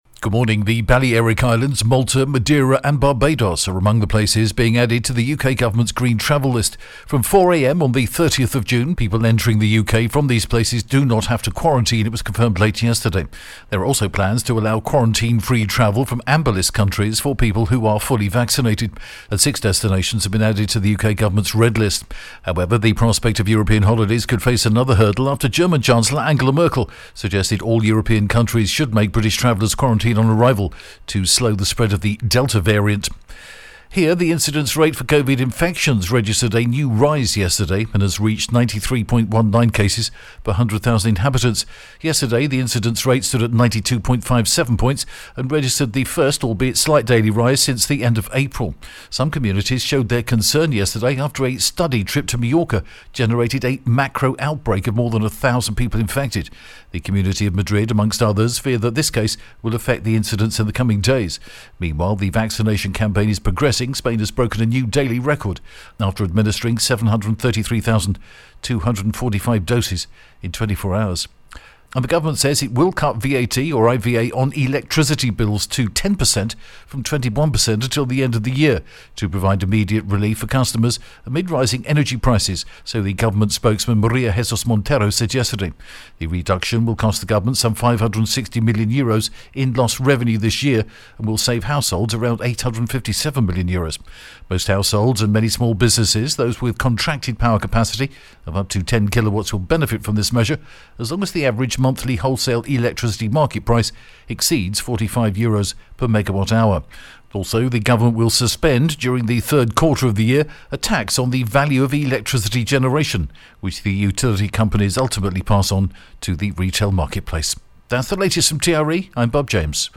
The latest Spanish news headlines in English: 24th June 2021 AM